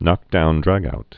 (nŏkdoundrăgout)